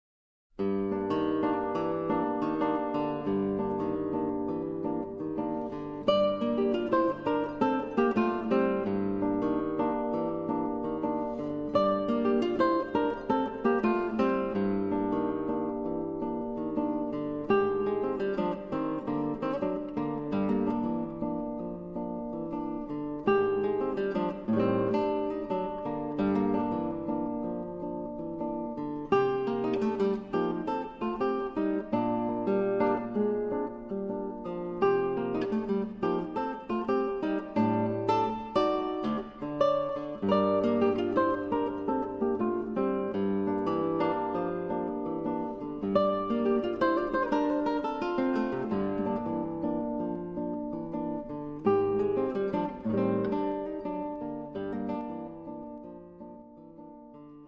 SOLO GUITAR WORKS